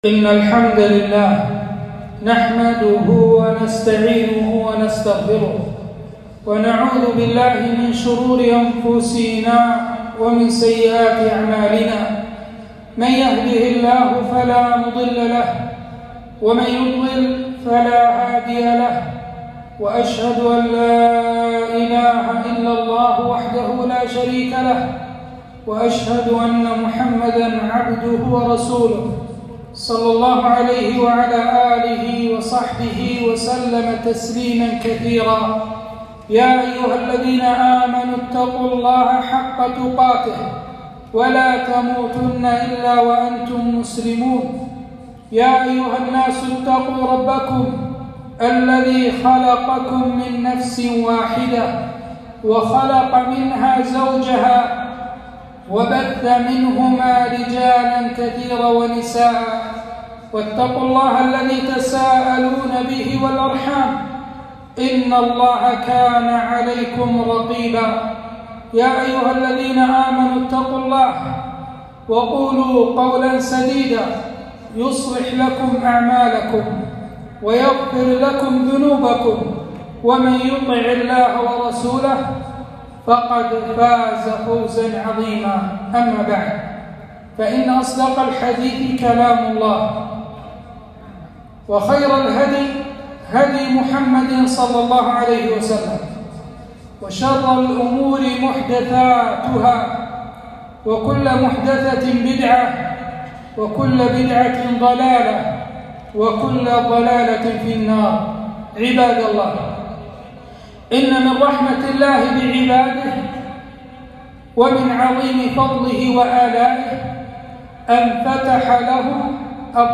خطبة - جريان الحسنات بعد الممات